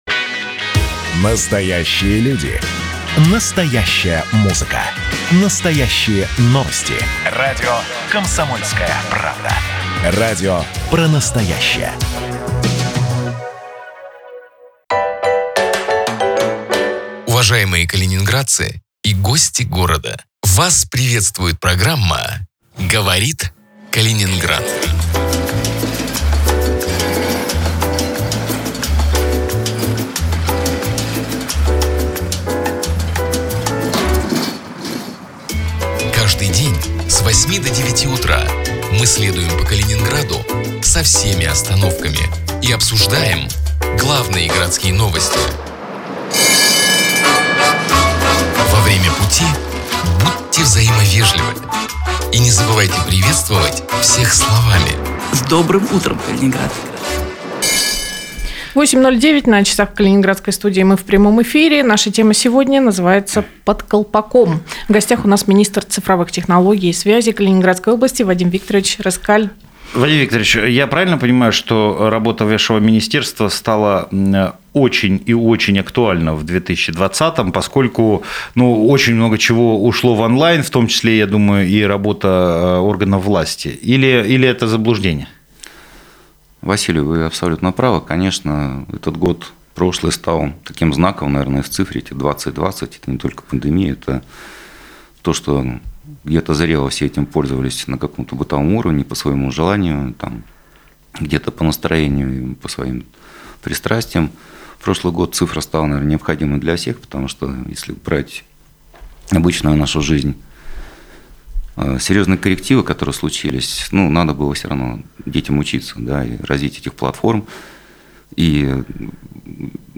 Каждое утро в прямом эфире обсуждаем городские новости.